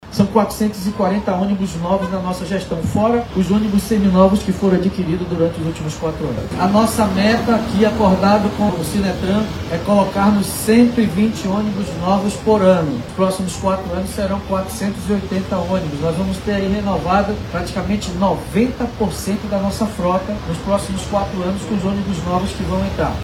A cerimônia ocorreu na Avenida das Torres, no sentido Cidade Nova/Coroado, com o objetivo de melhorar a mobilidade urbana.
Durante o pronunciamento, David destacou que os novos ônibus são modernos, oferecendo maior conforto e segurança aos usuários do transporte coletivo.